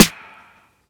tew_snr.wav